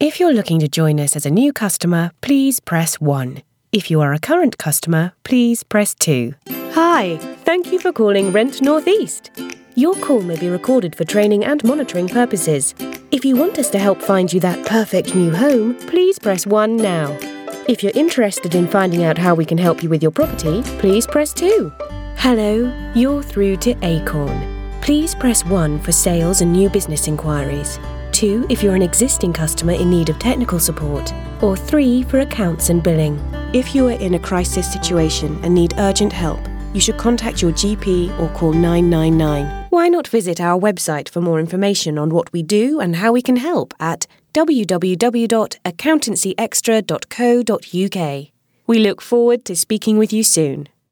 English (British)
IVR
My warm, authentic and yet professional tone is a popular choice with clients. I'm versatile, easy to work with and my broadcast quality studio wont let you down.
Mezzo-Soprano